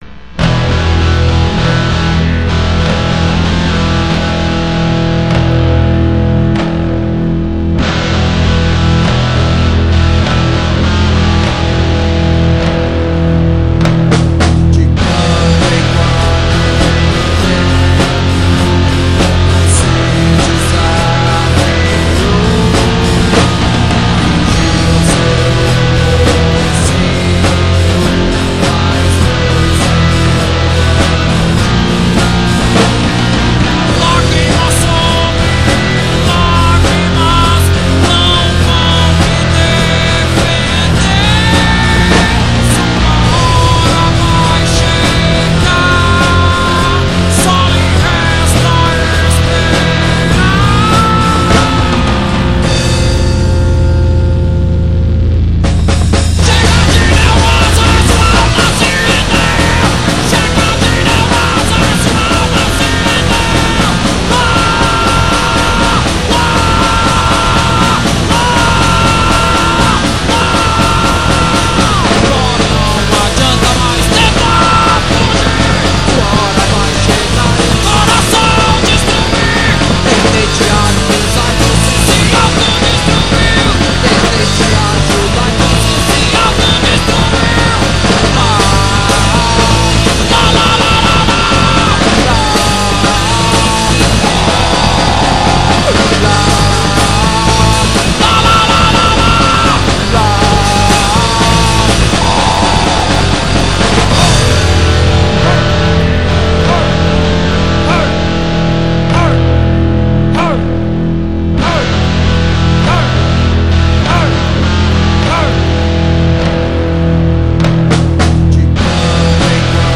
EstiloGrunge